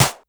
Snare_12.wav